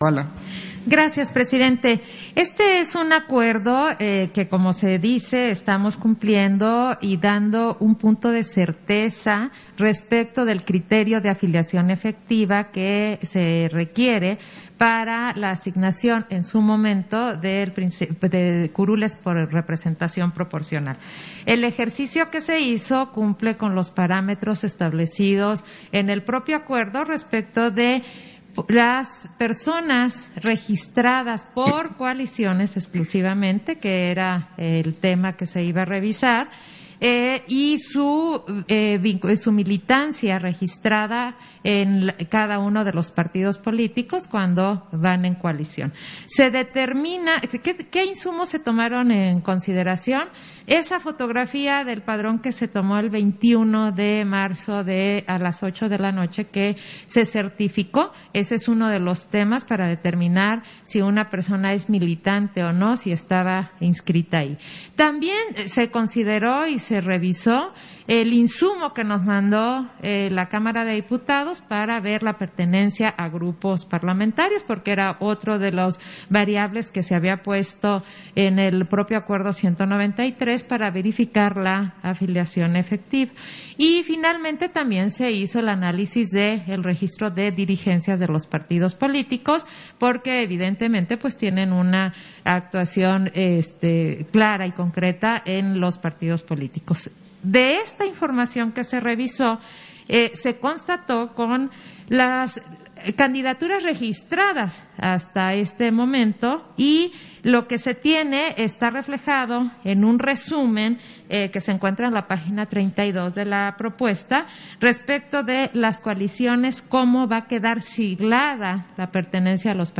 Intervención de Claudia Zavala, en el punto 5 de la Sesión Extraordinaria, por el que se determina el cumplimiento del mecanismo de asignación de curules por el principio de representación proporcional